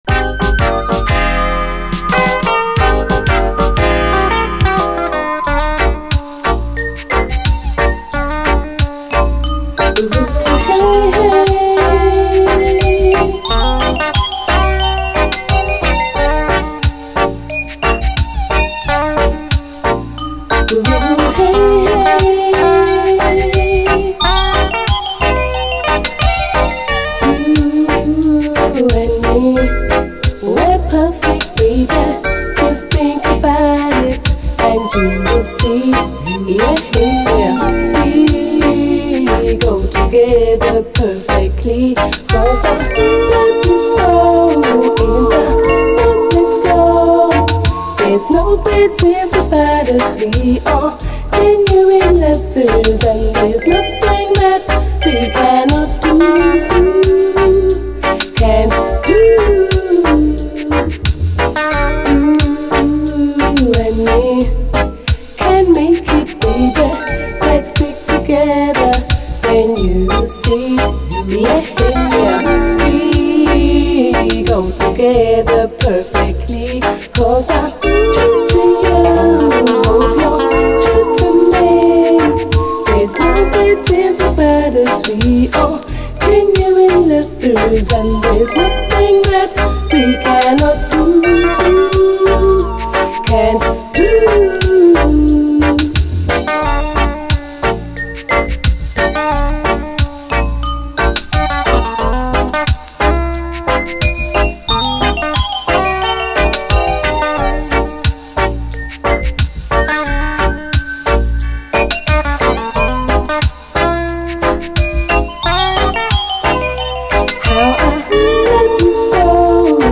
REGGAE
試聴ファイルはこの盤からの録音です/
可愛らしくて切ないメロディー＆サウンド、人気の一曲！後半のダブも可愛らしい。